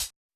hihat.wav